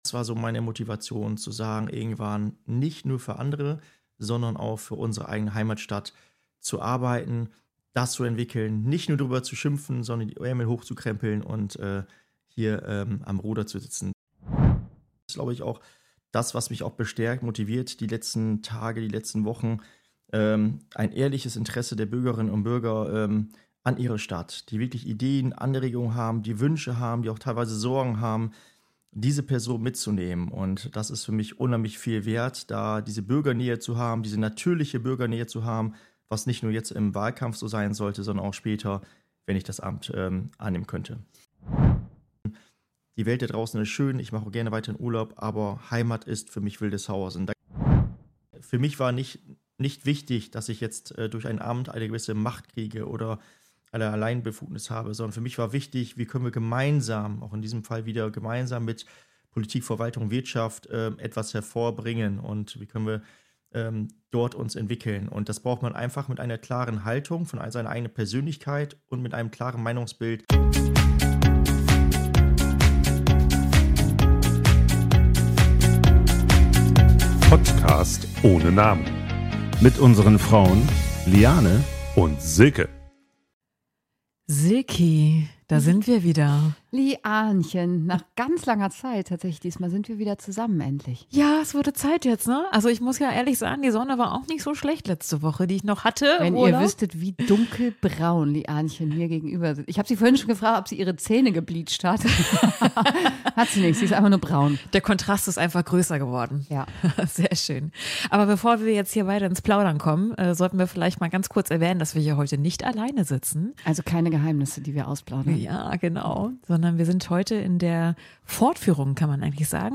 Und wie geht man mit Erwartungen, Kritik und öffentlichem Druck um? Ein persönliches Gespräch über Motivation, Haltung und die Frage, warum Wählen wichtig ist.